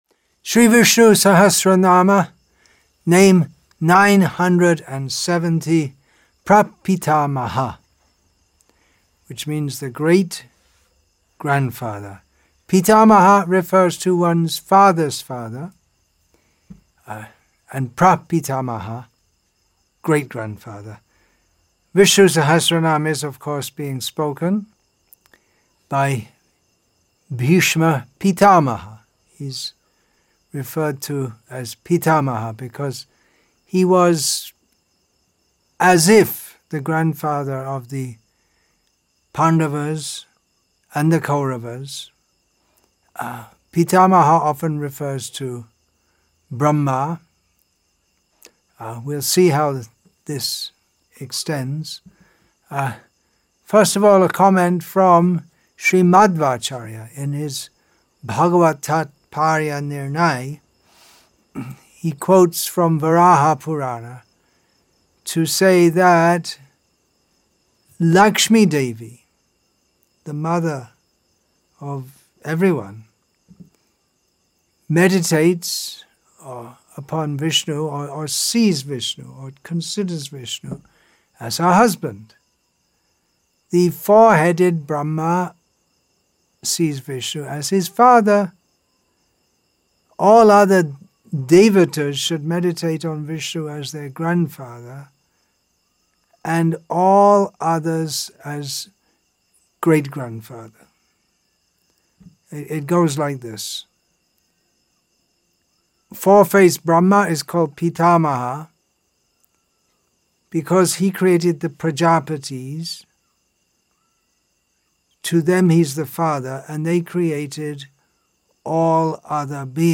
Karumandurai, Salem, Tamil Nadu , India